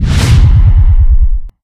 anomaly_gravy_blast1.ogg